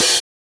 OpenHH Groovin 2.wav